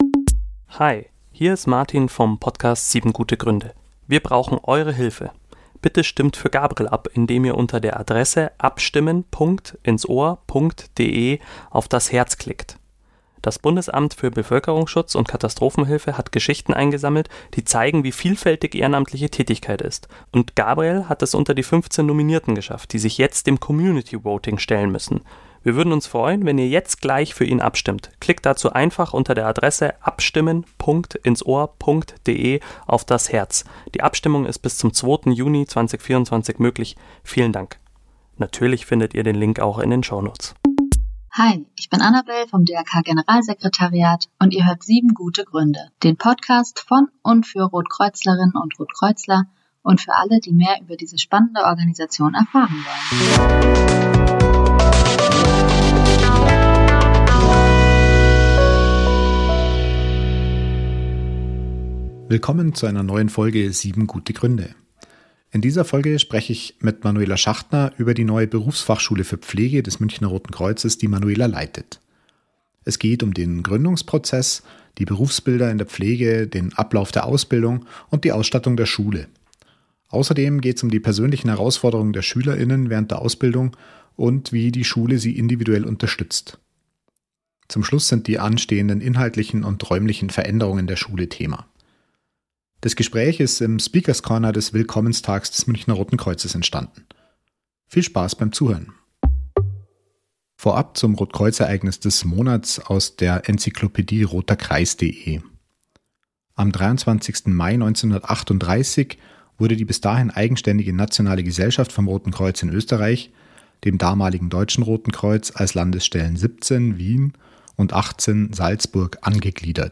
Außerdem geht es um die persönlichen Herausforderungen der Schüler:innen während der Ausbildung und wie die Schule sie individuell unterstützt. Zum Schluss sind die anstehenden inhaltlichen und räumlichen Veränderungen der Schule Thema. Das Gespräch ist im Speakers Corner des Willkommenstags des Münchner Roten Kreuzes entstanden.